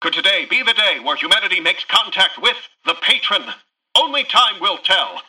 Newscaster_headline_18.mp3